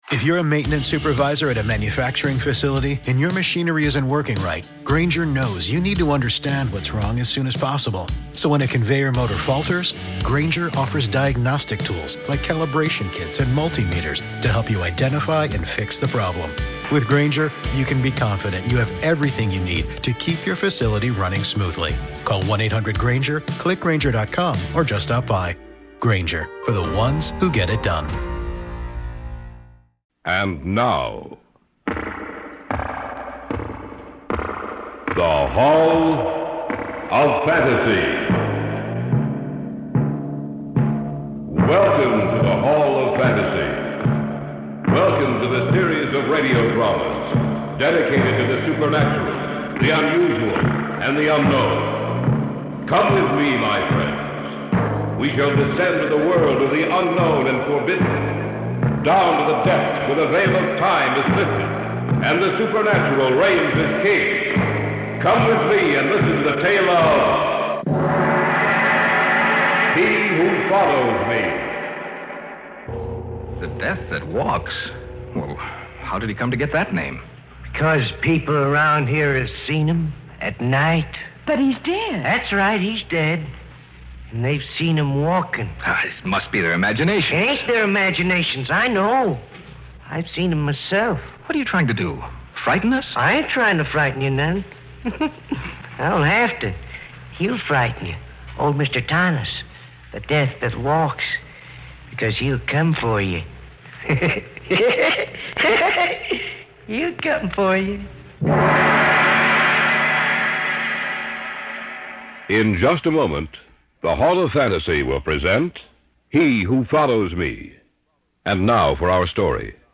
On this week's episode of the Old Time Radiocast we present you with two stories from the classic radio program The Hall of Fantasy.